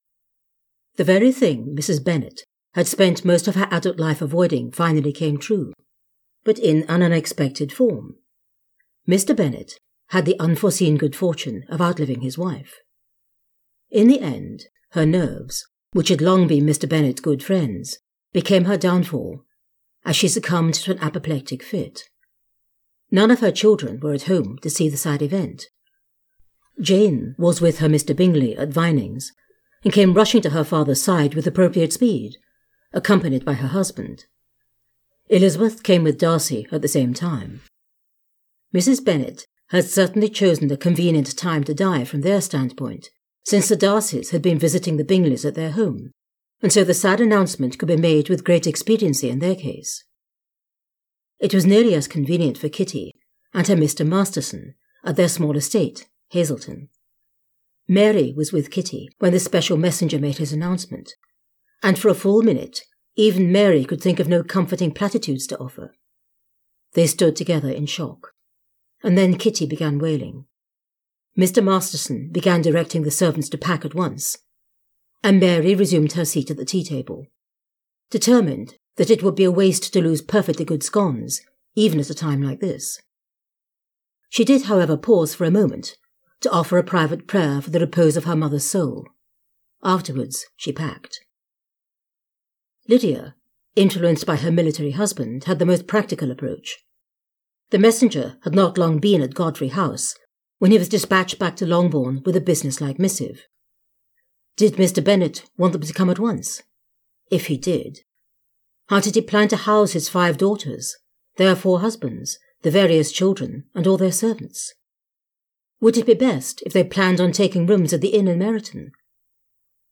The An Unexpected Turn of Events audiobook is available on Amazon, Audible and iTunes.